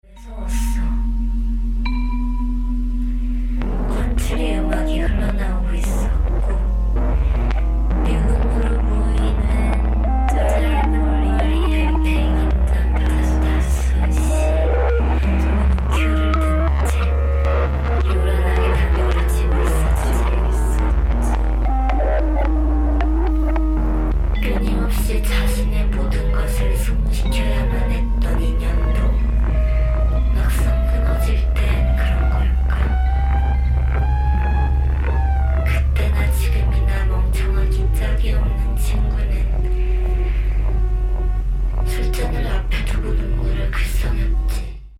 to minimalist bangers